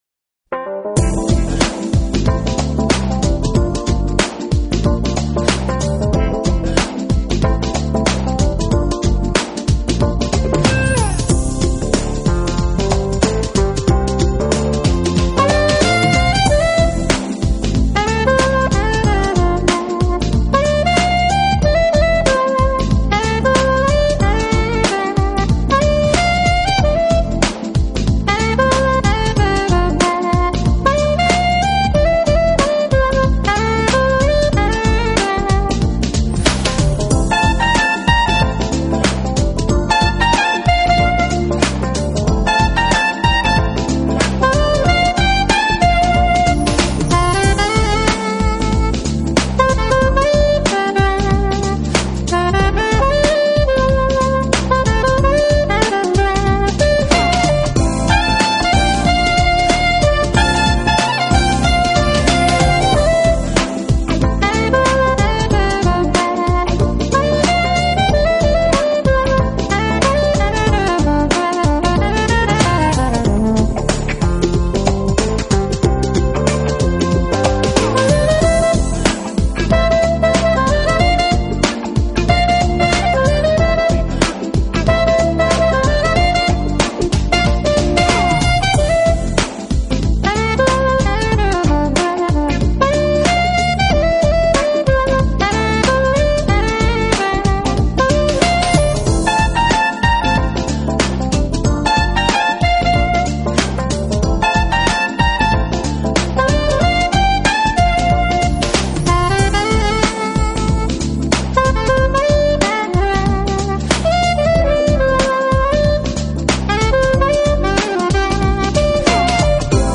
演奏风格包容了Jazz、R&B、Pop，不但在商业上获得成功，同时更为日后所谓